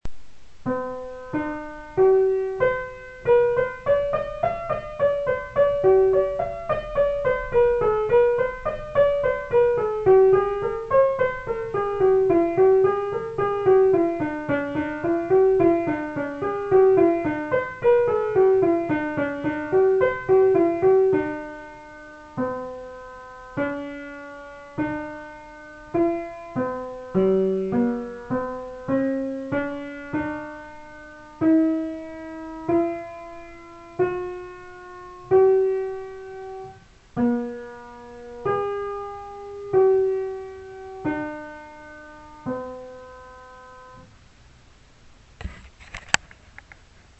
Listen here to the Royal theme backwards